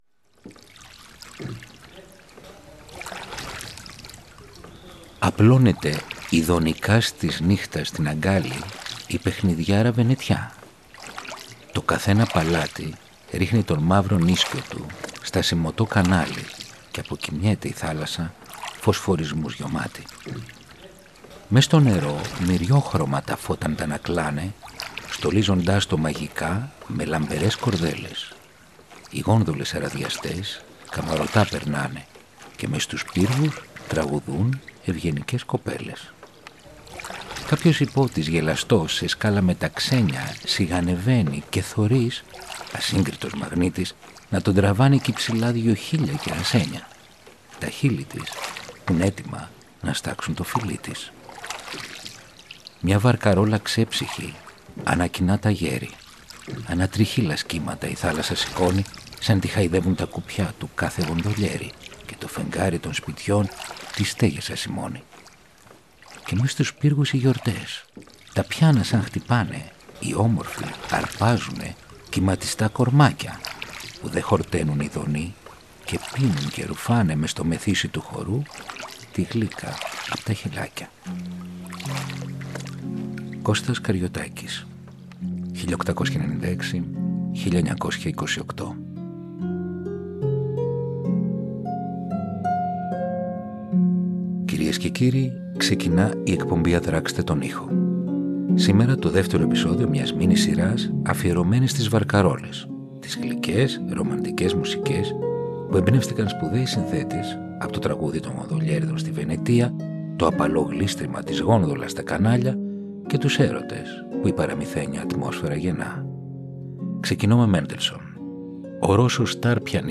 Βαρκαρόλες. Επεισόδιο 2ο “Ασημωτό κανάλι”.